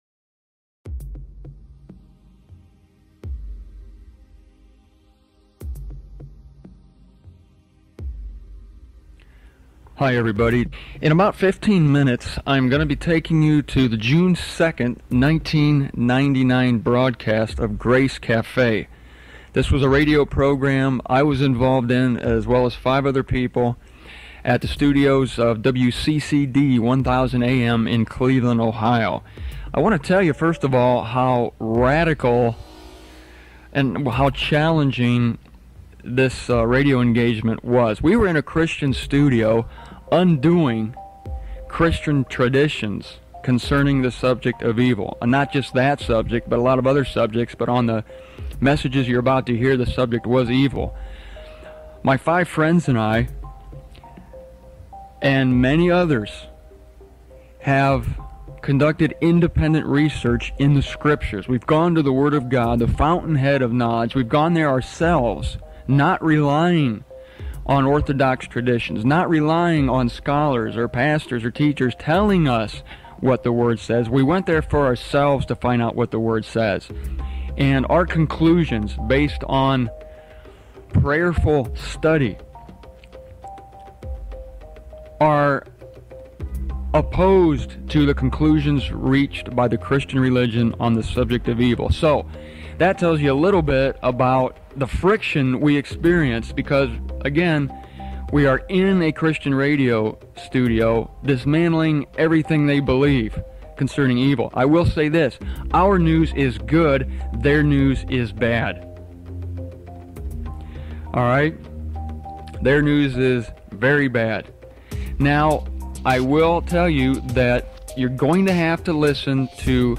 The audio presented here is taken from a live radio program that I and five others broadcast from the largest Christian radio station in Cleveland, OH: WCCD 1000 AM. In this first part of a four-part series, we tackle the subject of the purpose of evil in God's universe.
Especially helpful are the extensive introductory remarks I recorded in my home studio before the broadcast.